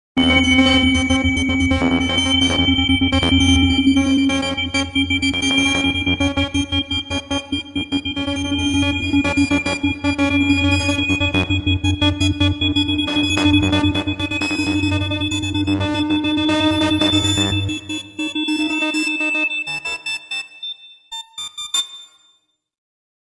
电气效果 5 " 040602A
描述：各种数字振荡器声音的集群，具有重复、传播、平滑转移的元素。
标签： 低音 效果 振荡器 重复 序列 载体
声道立体声